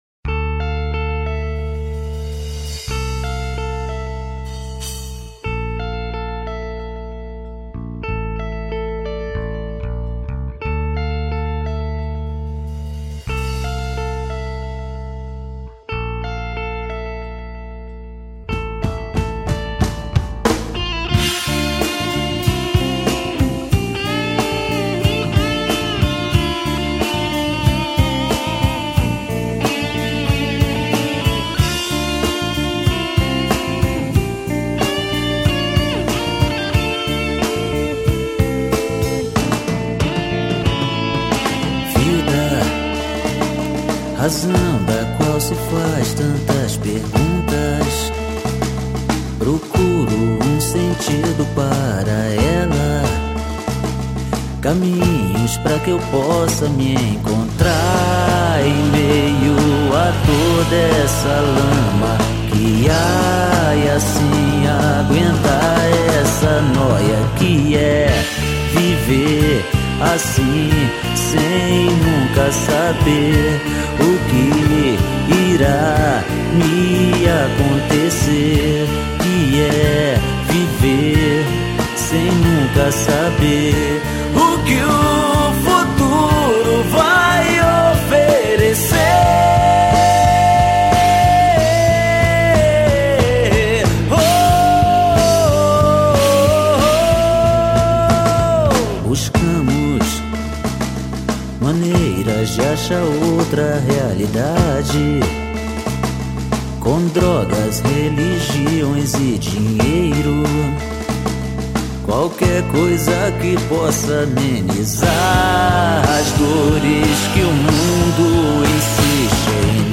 EstiloAlternativo